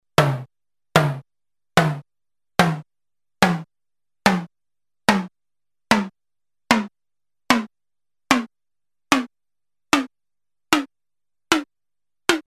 Digital Drum Machine (1987)
- pitch tuning
HEAR tom pitch